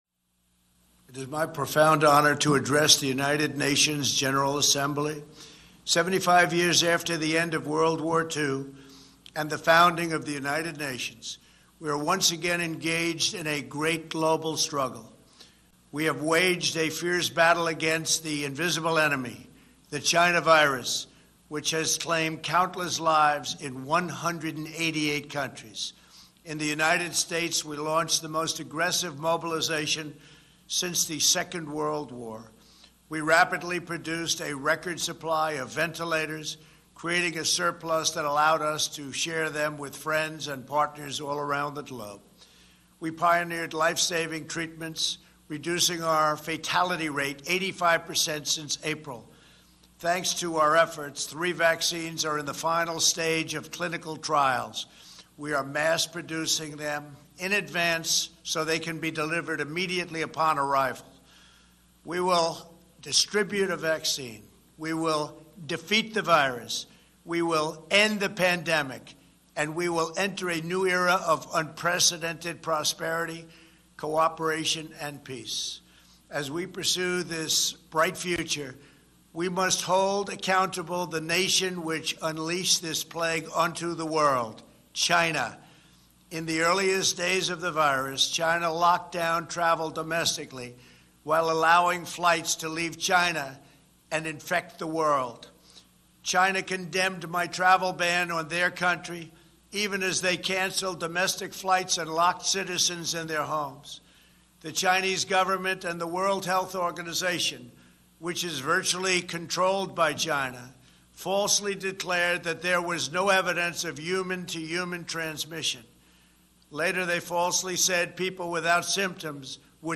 Prerecorded Address to the 75th United Nations General Assembly